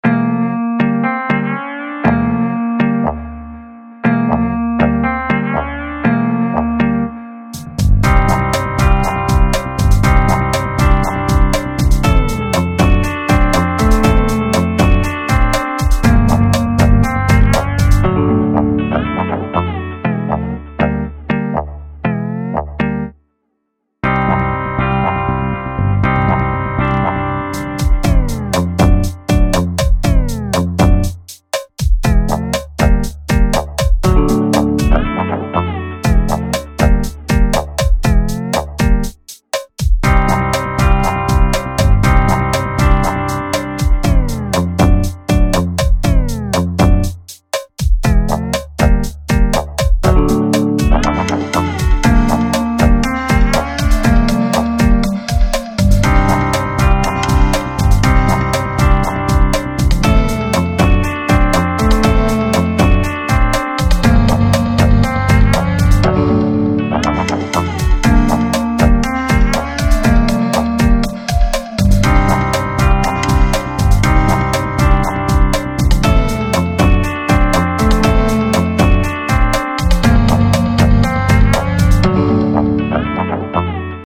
Минус Летчик